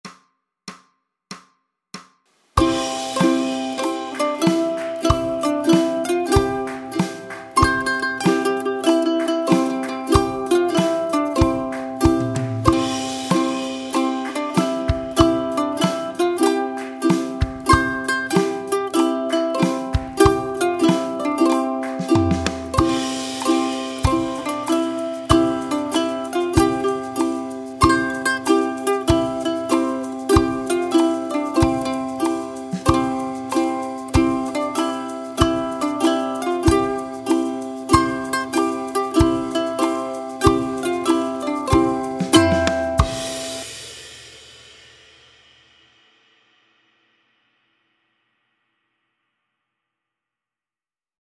Backing track
C (continue strumming throughout)